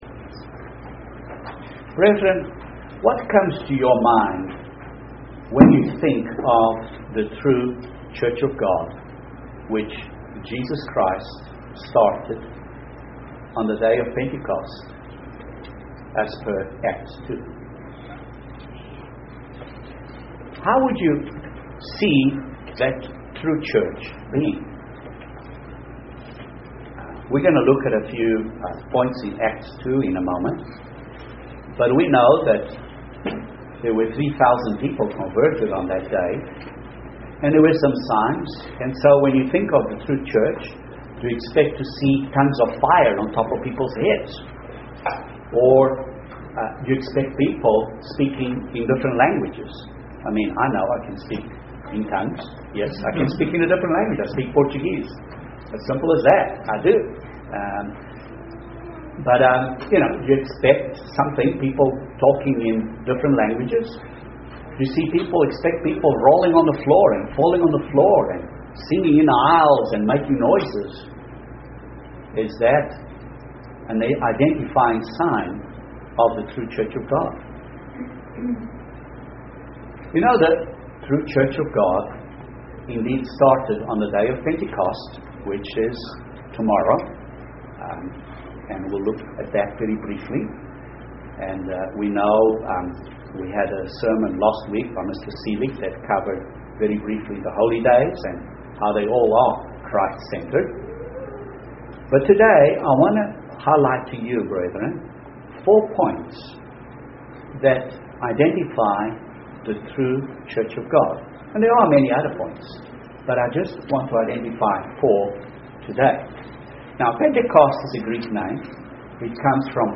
A few principles about how to identify God's True Church UCG Sermon Transcript This transcript was generated by AI and may contain errors.